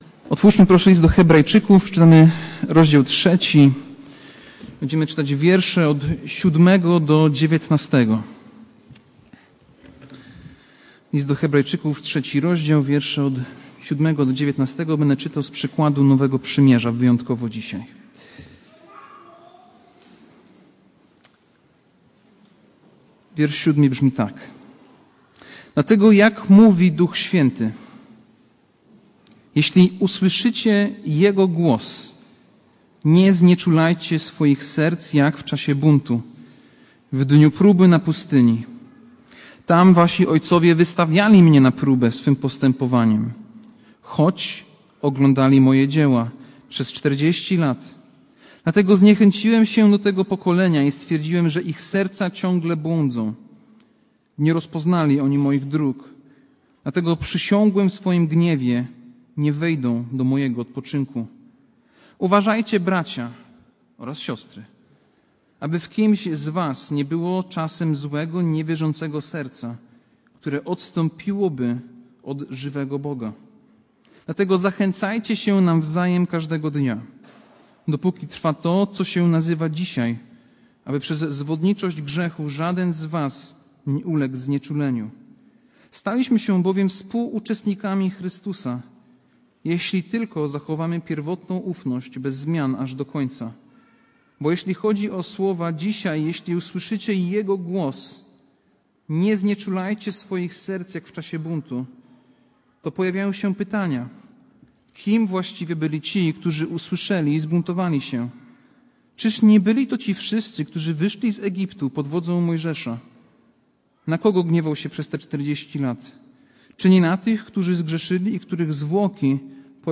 Passage: List do Hebrajczyków 3, 7-19 Kazanie